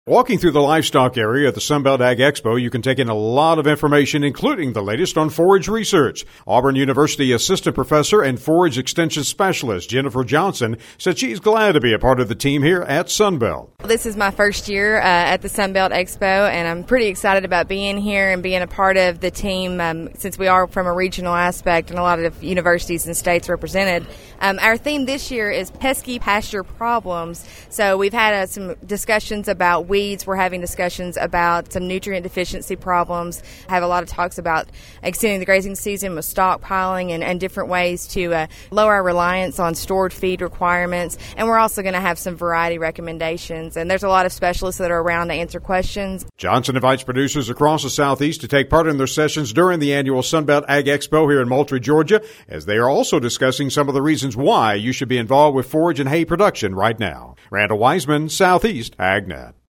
The cattle pavilion on the grounds here at the Sunbelt Ag Expo has been a busy place as folks get a chance to see the various exhibits along with taking in a few seminars.